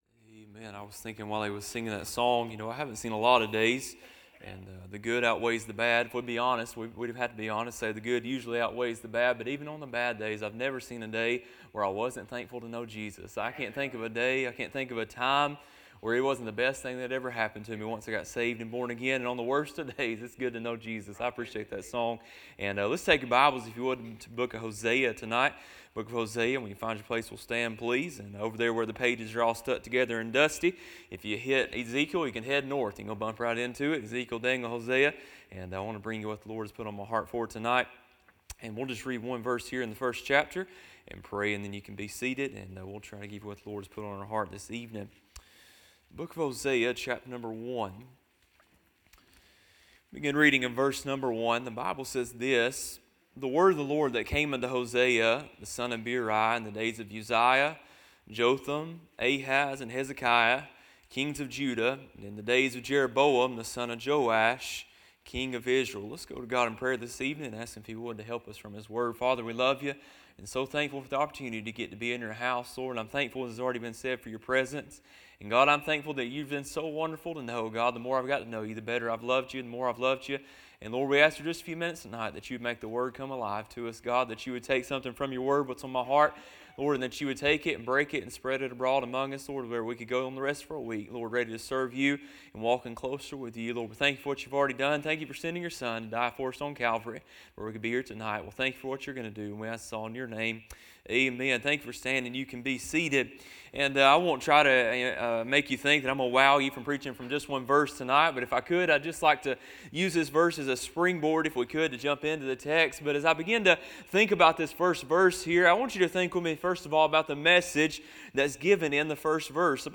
Listen to the most recent sermons from Bible Baptist Church